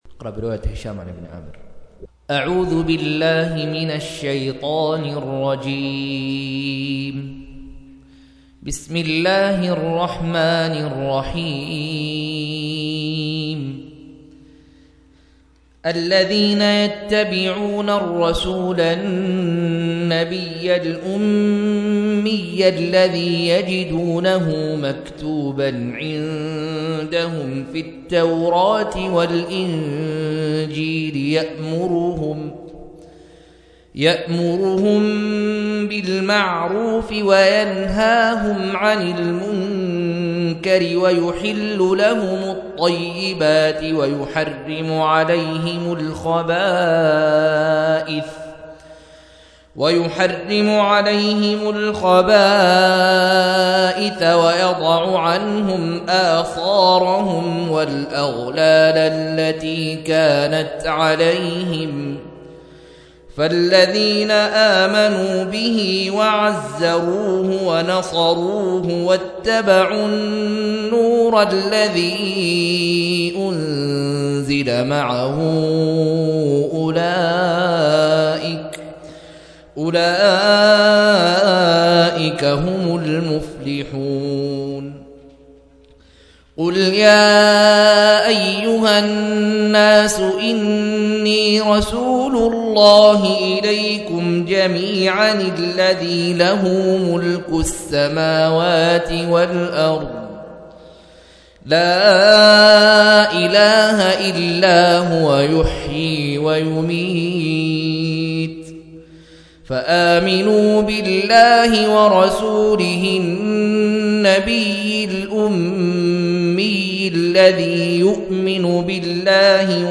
157- عمدة التفسير عن الحافظ ابن كثير رحمه الله للعلامة أحمد شاكر رحمه الله – قراءة وتعليق –